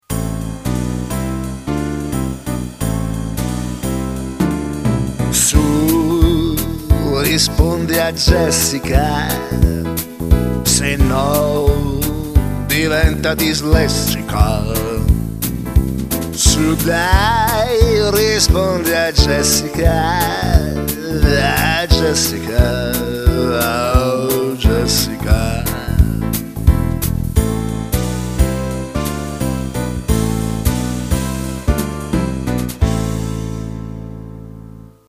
Una suoneria personalizzata che canta il nome